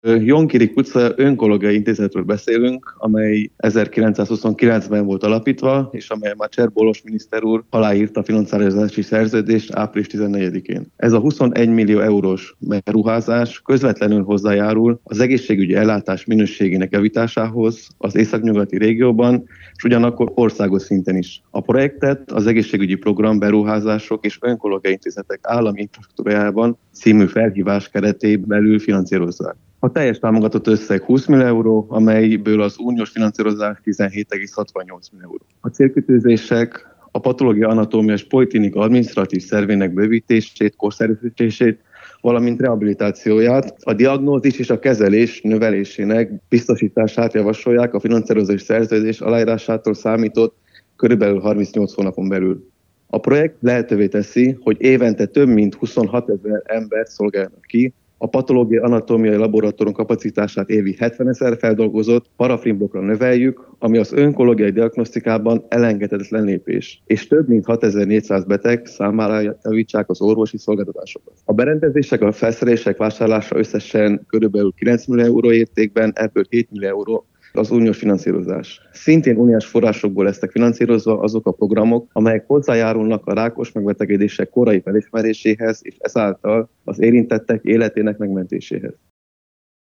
A fejlesztésről az Európai Beruházások és Projektek Minisztériumának államtitkárát, Kelemen Attilát kérdezte